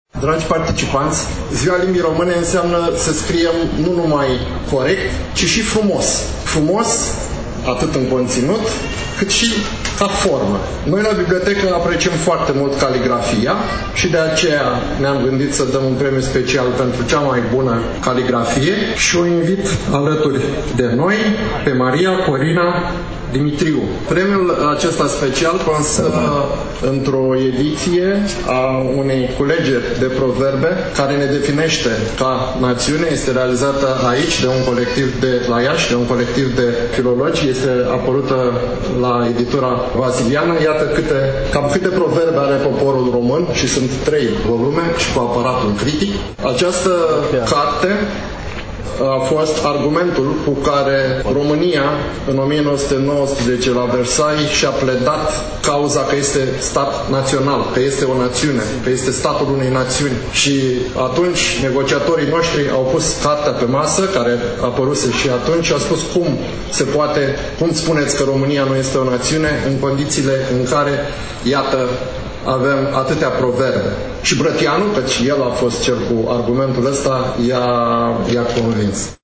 Stimați prieteni, relatăm, astăzi, de la „Marea Dictare”, dictare în aer liber, eveniment desfășurat la 31 august 2023, începând cu ora 10 în Parcul Expoziției din târgul Iașilor.